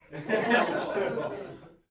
sound-hahaha